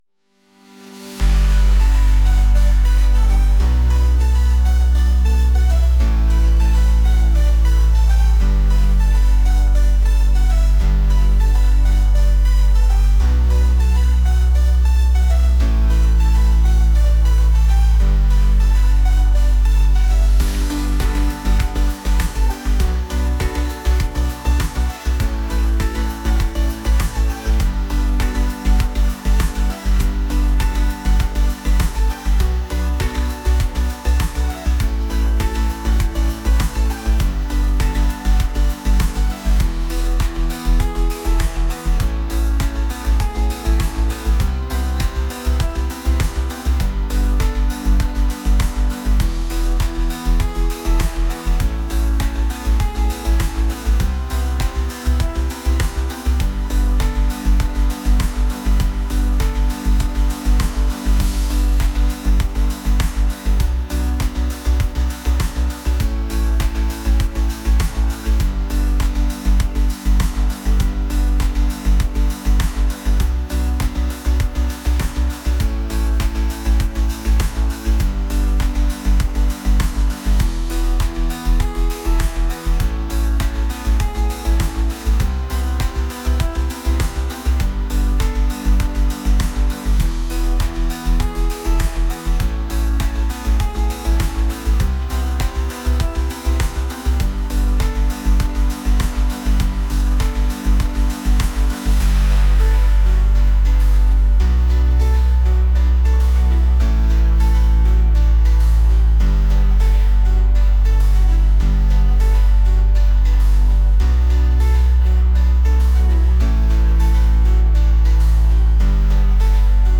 upbeat | electronic | pop